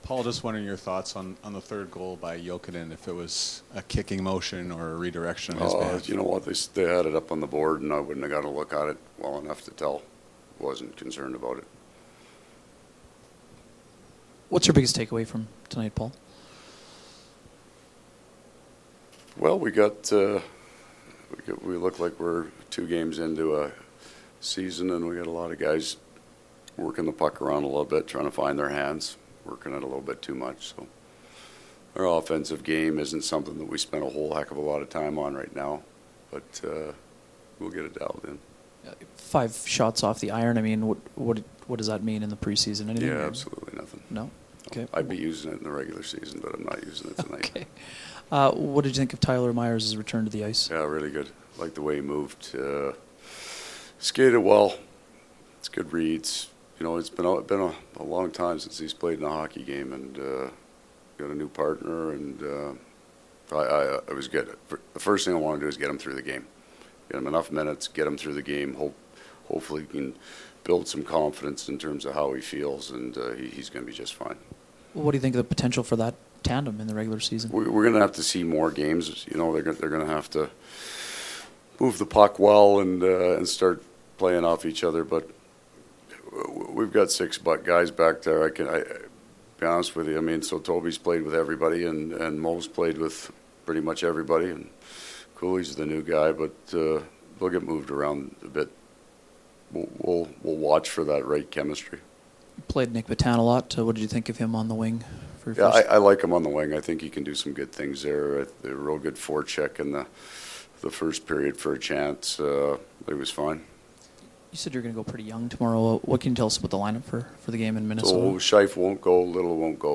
Post-game from the Jets dressing room as well as from Coach Maurice.